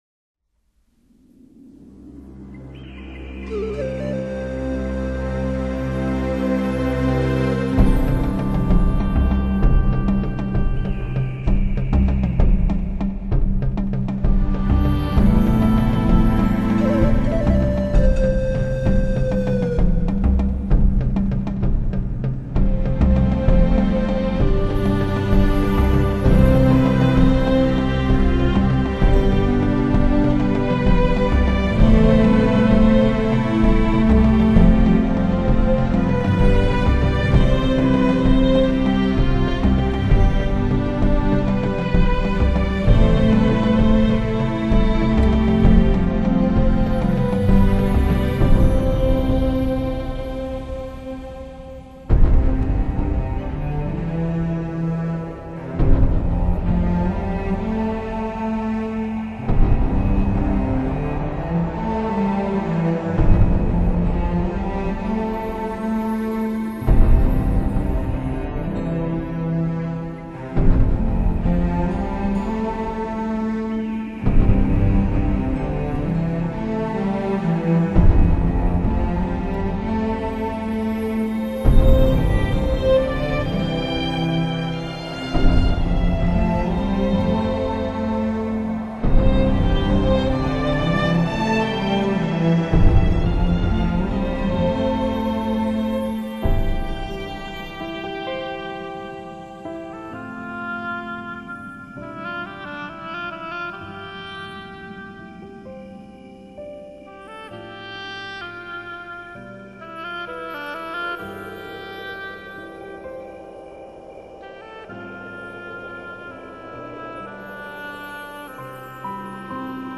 钢琴的演奏低缓而涌动，清脆而强硬。小提琴高亢而嘹亮，牵引着旋律的丝线逐步高升。